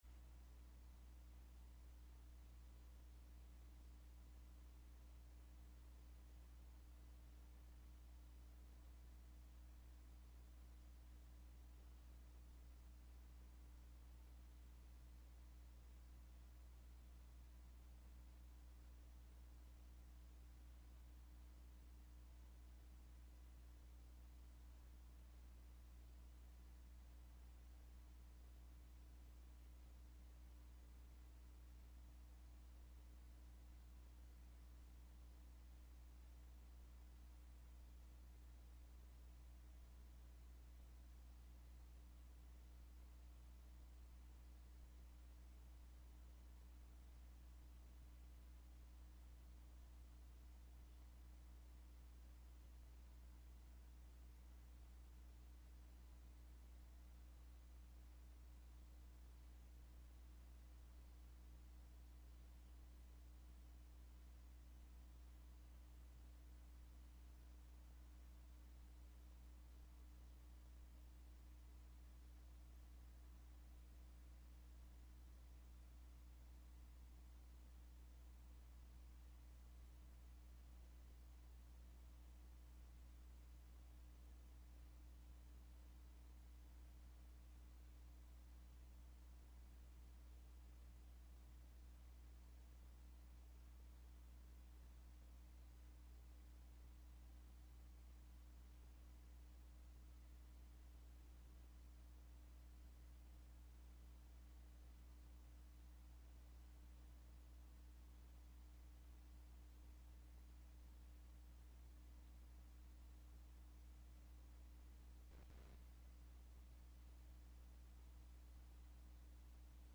Location: Las Vegas Before the Southern Panel, Justice Saitta Presiding